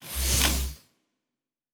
Sci-Fi Sounds / Doors and Portals / Door 2 Close.wav
Door 2 Close.wav